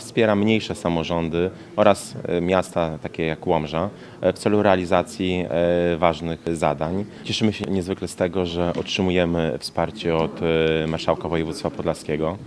Łomża otrzymała 1,5 miliona na budowę sali sportowej przy Szkole Podstawowej nr 5. Prezydent Łomży, Mariusz Chrzanowski podkreśla, że fundusz wsparcia gmin i powiatów to niezwykle cenna inicjatywa.